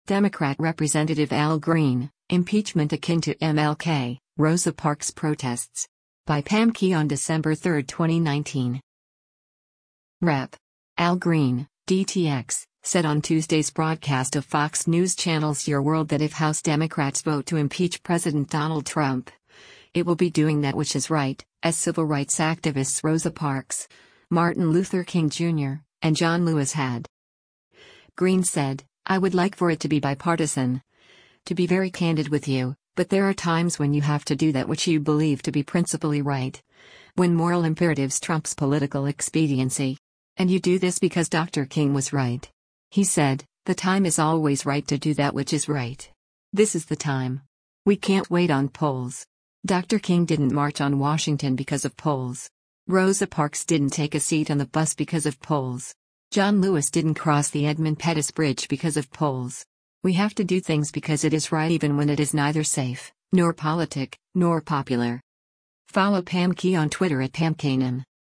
Rep. Al  Green (D-TX) said on Tuesday’s broadcast of Fox News Channel’s Your World that if House Democrats vote to impeach President Donald Trump, it will be doing “that which is right,” as civil rights activists Rosa Parks, Martin Luther King Jr., and John Lewis had.